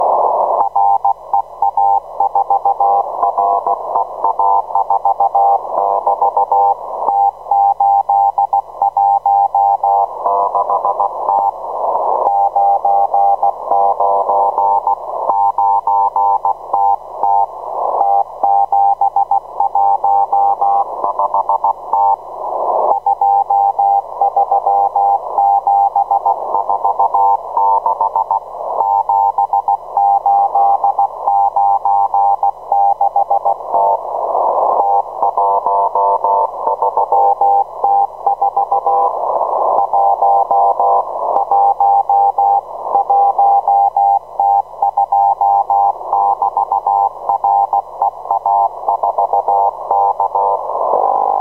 Émissions télégraphiques exotiques
Chalutiers présumés japonnais sur 4191 kHz et commandement de l'aviation à longue portée des forces aériennes russes sur 4179 kHz :